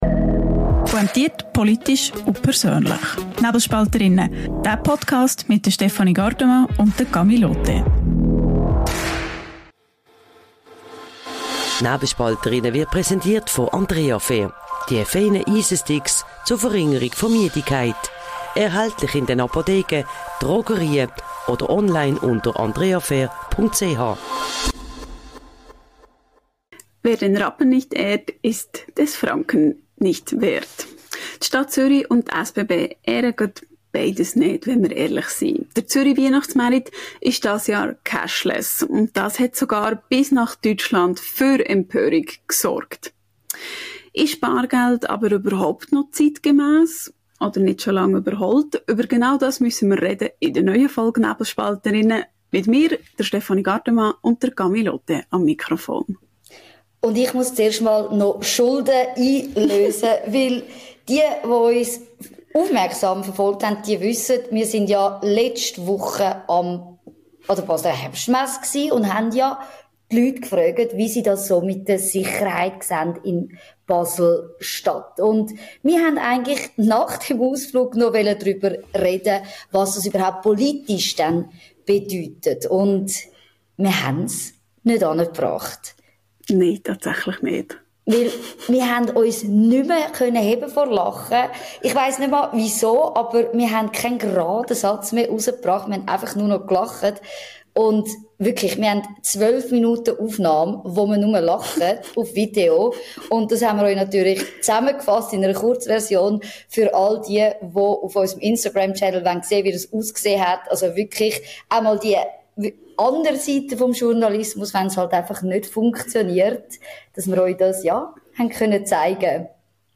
Ein Gespräch über Freiheit, Gewohnheit und die Sorge um das mögliche Ende des Bargelds.